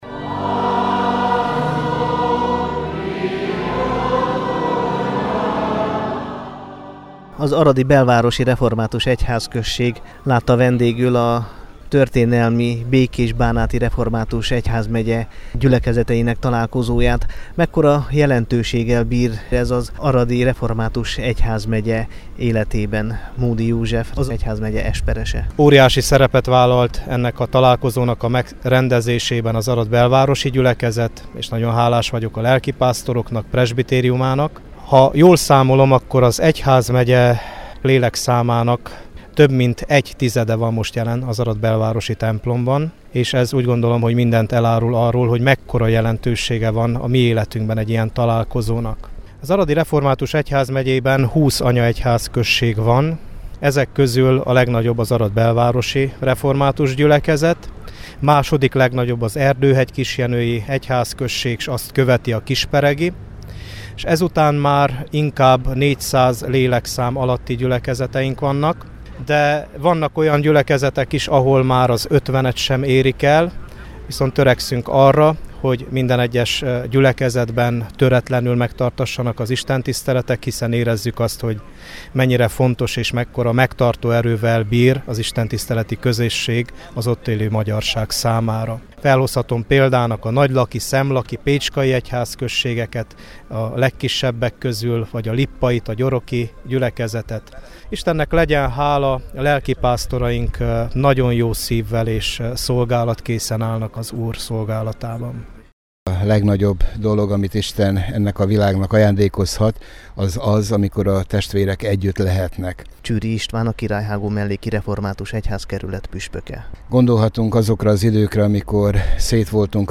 Ezúttal az arad-belvárosi református egyházközség adott otthont a találkozónak.